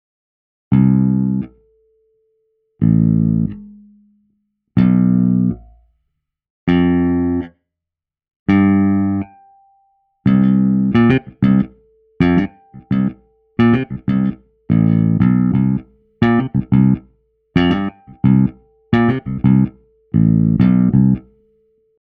378 Mb recorded at 16 bit 44 Khz directly to the sound card without any amplifiers and efx.
Release_noises.mp3